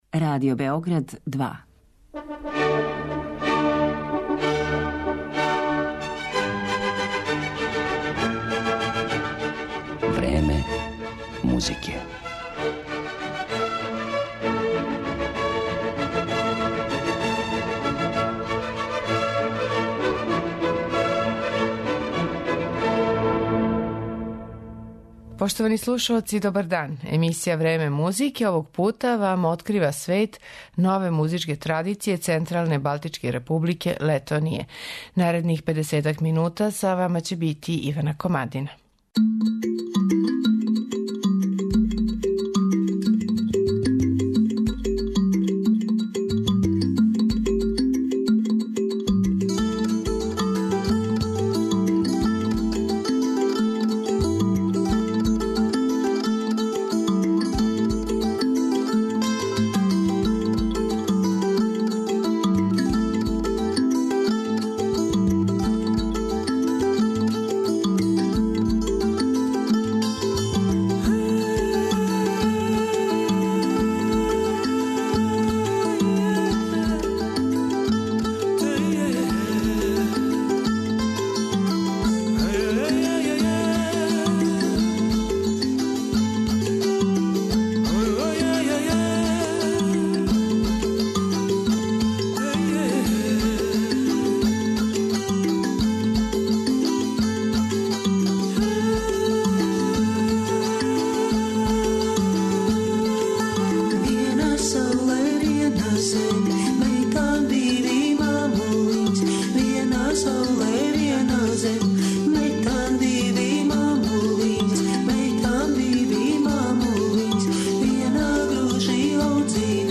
Летонски постфолклор